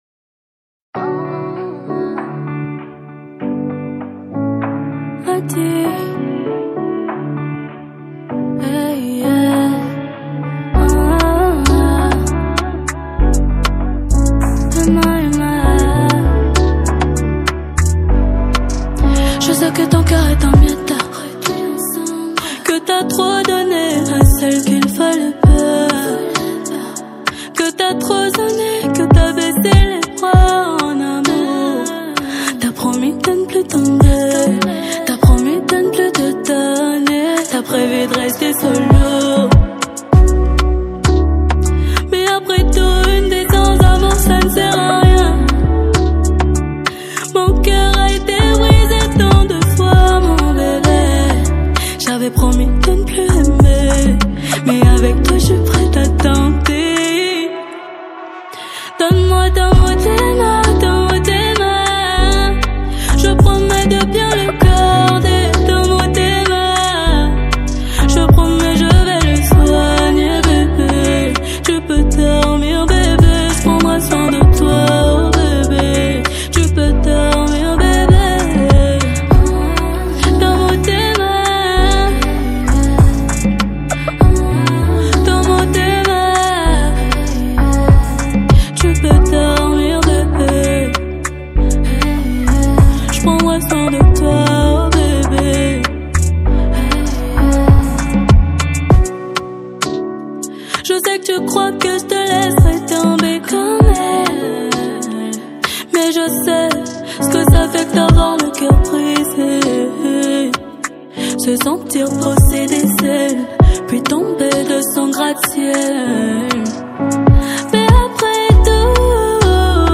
| Afro zouk